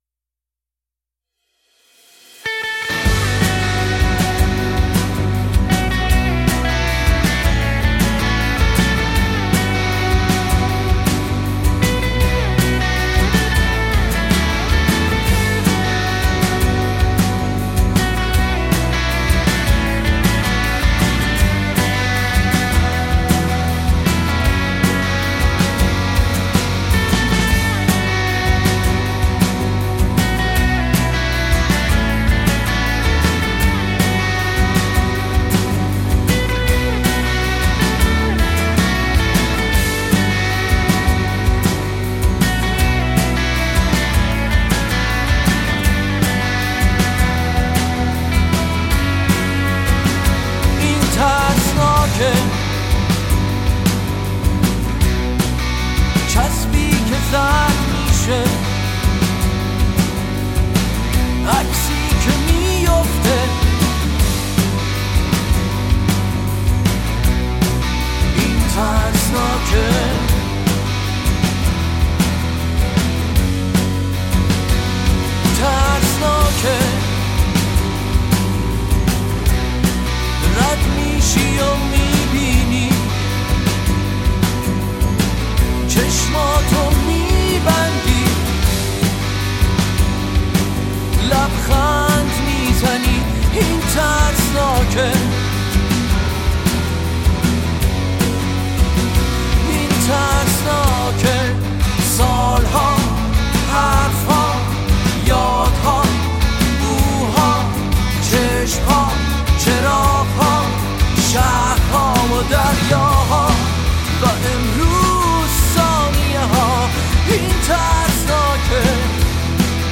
آهنگ ایرانی
آهنگ راک